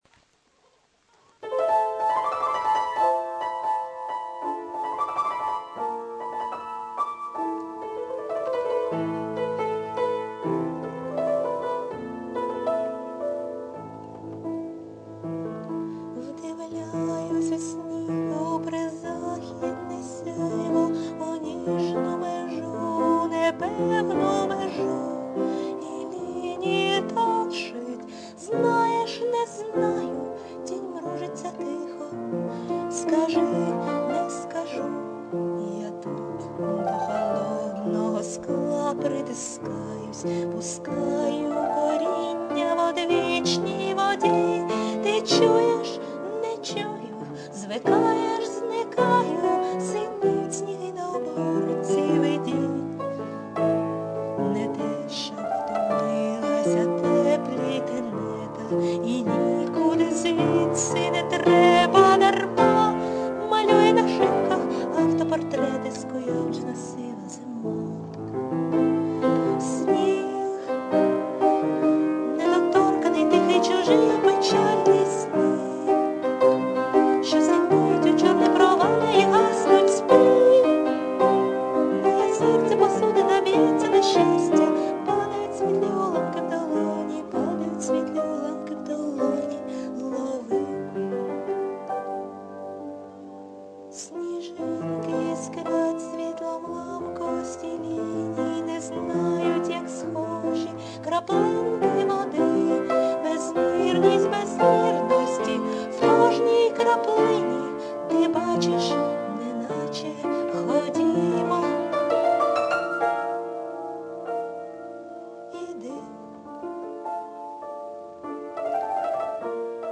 Співана поезія! 12
Поки що є тільки домашні записи.